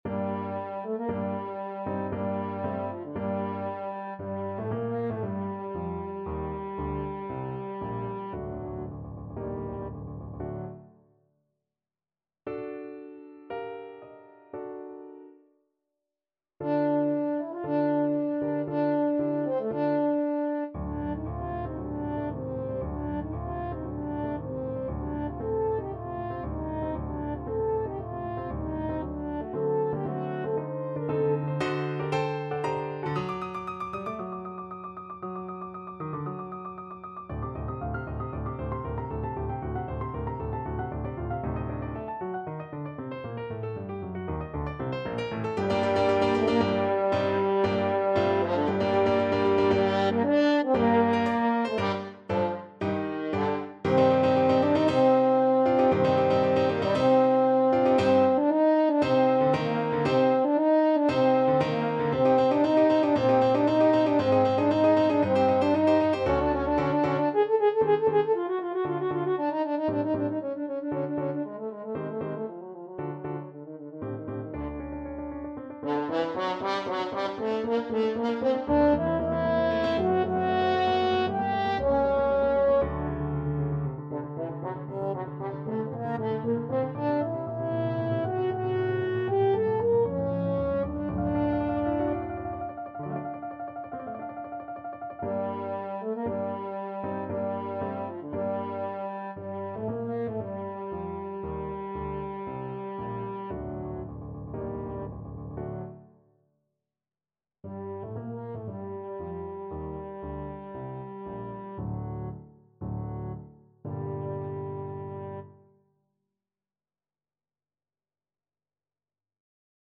Classical Dvořák, Antonín Concerto for Cello Op.104, 1st Movement Main Theme French Horn version
G minor (Sounding Pitch) D minor (French Horn in F) (View more G minor Music for French Horn )
4/4 (View more 4/4 Music)
Allegro =116 (View more music marked Allegro)
Classical (View more Classical French Horn Music)